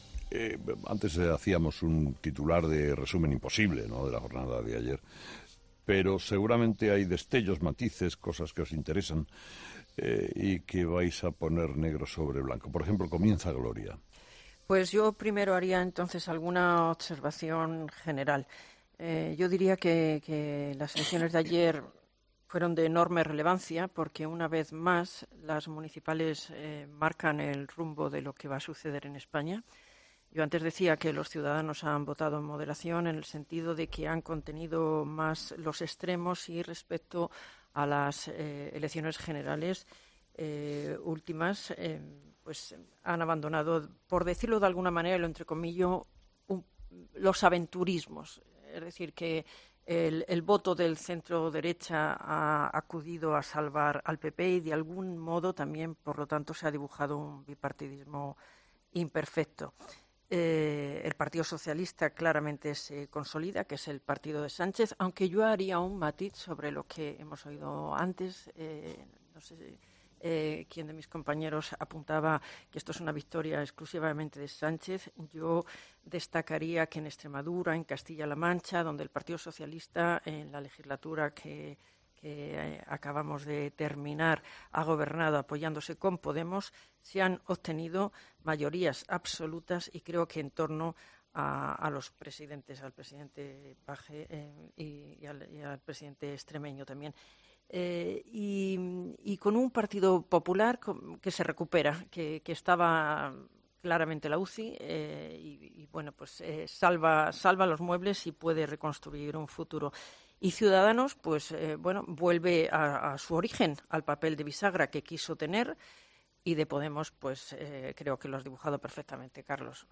Los tertulianos de Herrera analizan el 26-M
Los tertulianos de 'Herrera en COPE ' han analizado este lunes los resultados de las elecciones del 26 de mayo.